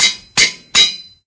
anvil_use.ogg